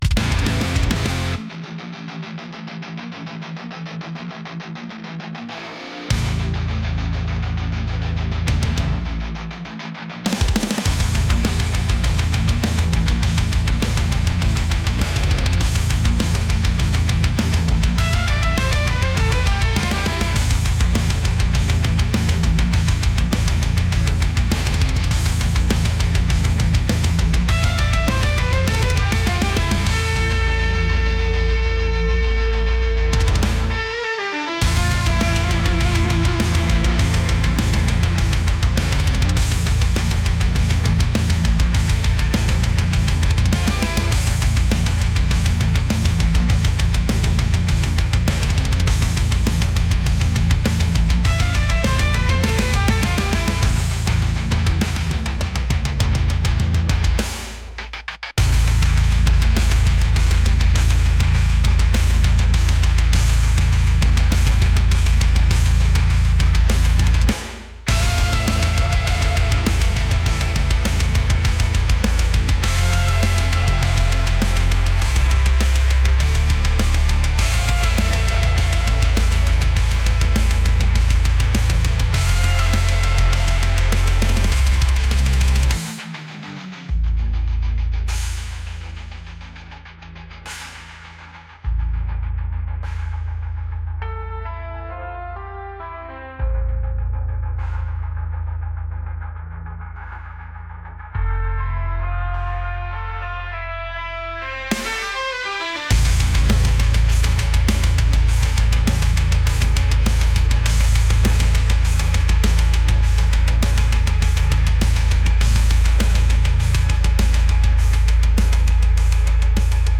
aggressive | metal